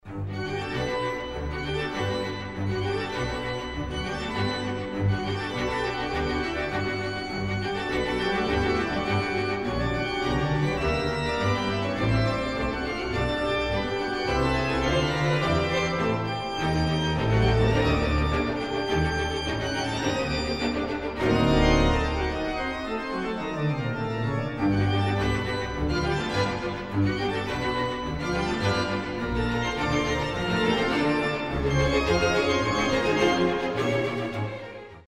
at St. Augustine's Chapel, Tonbridge School.